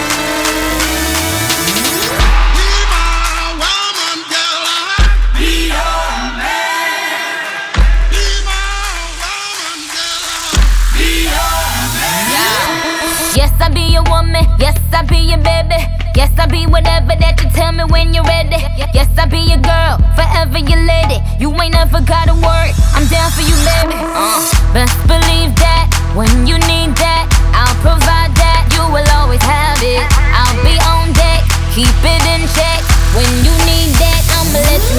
Жанр: Электроника
# Electronic